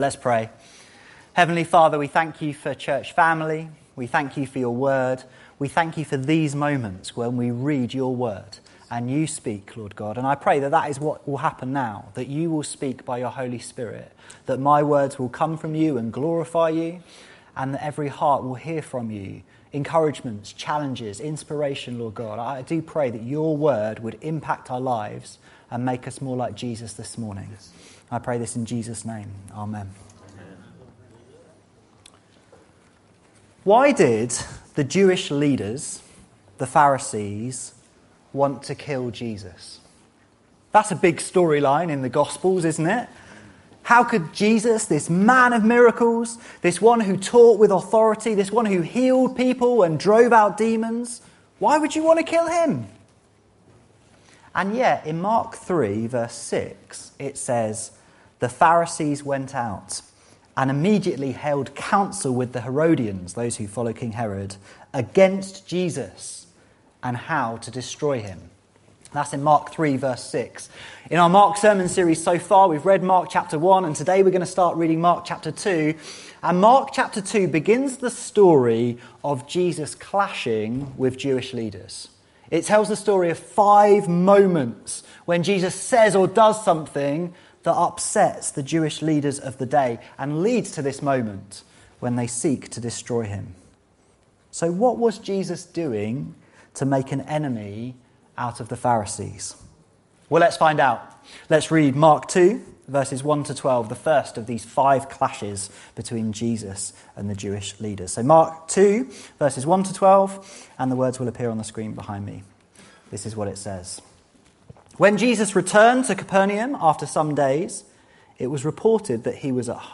This sermon demonstrates to us Jesus has the authority and willingness to provide forgiveness of our sins.